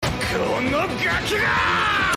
Play Diavolo Screams - SoundBoardGuy
diavolo-screams-1.mp3